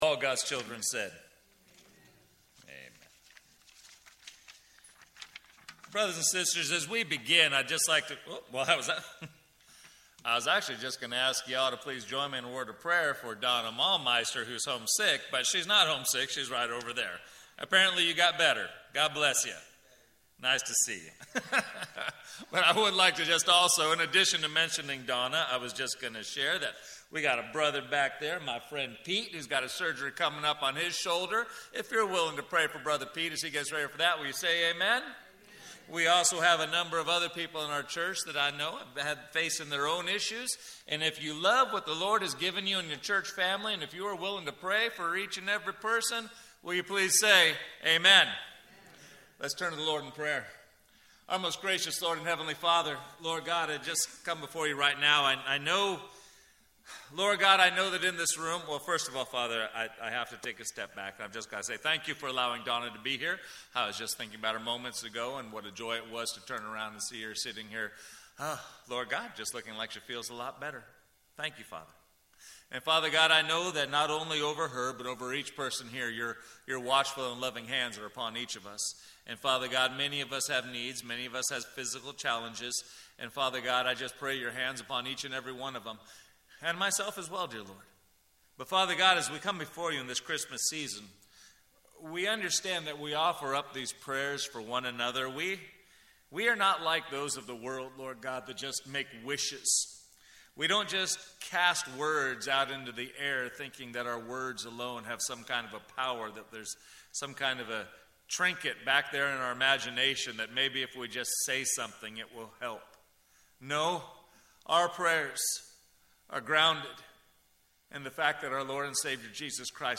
Sermons - First Baptist Church Solvang
Note: First 15 seconds of recording is silence